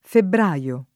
febbr#Lo] (region. antiq. febbraro [